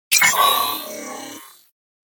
ogg / general / combat / aircraft / attack.ogg